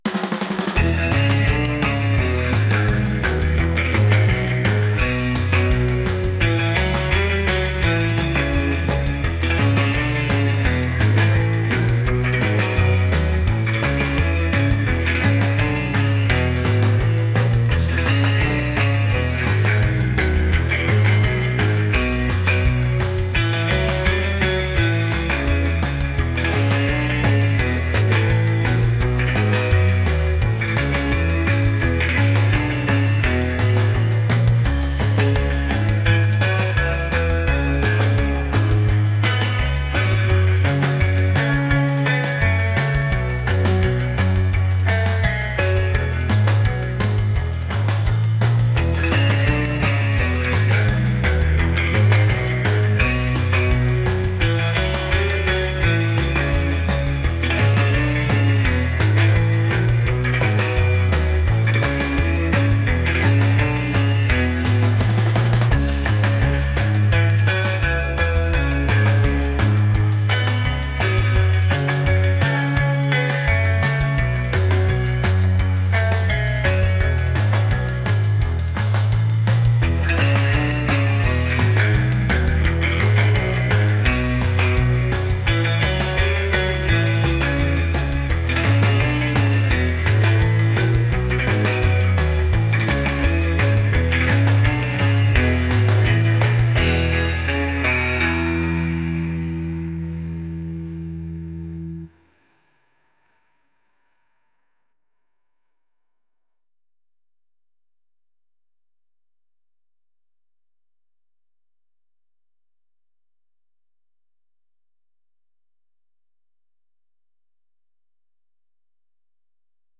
energetic | retro | rock